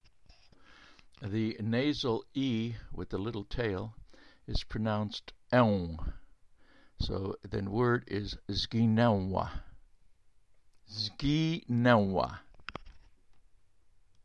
Polish Words -- Baritone Voice
zginęła (zgee - NEHoo - wah)